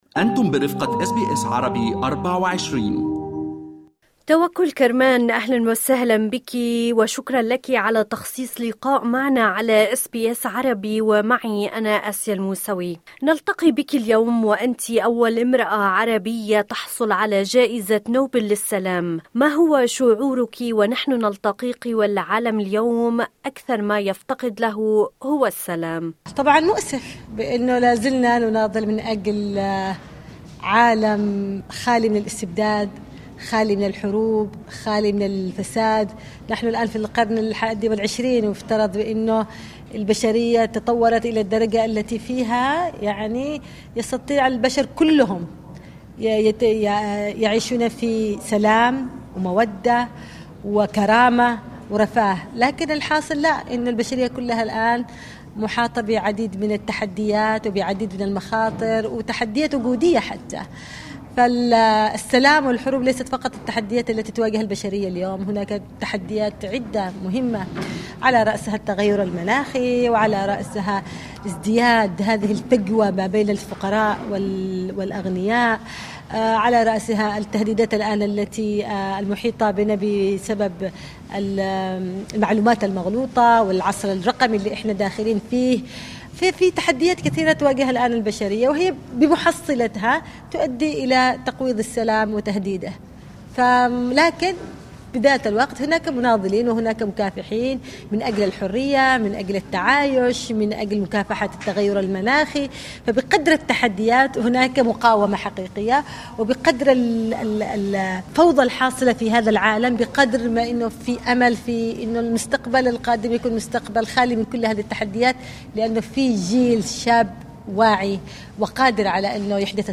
"أعتز بلقب أم الثورة": توكل كرمان الحاصلة على جائزة نوبل للسلام في لقاء مع أس بي أس